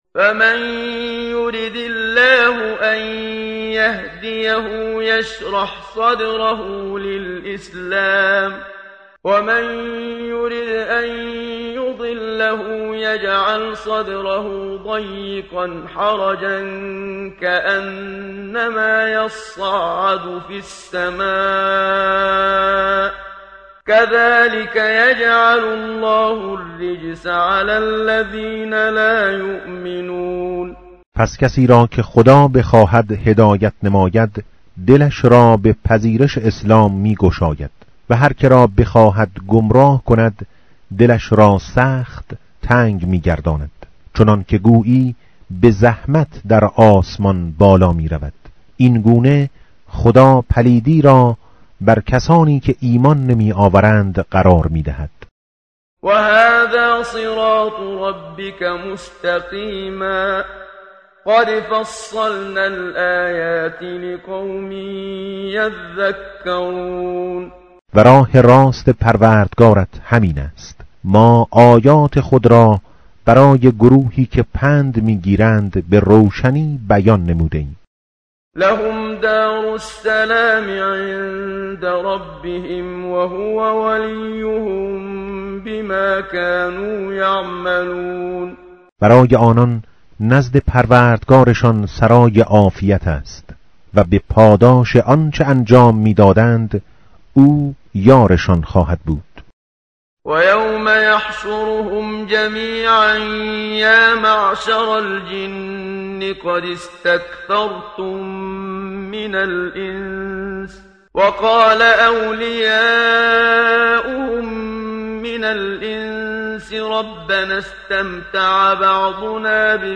tartil_menshavi va tarjome_Page_144.mp3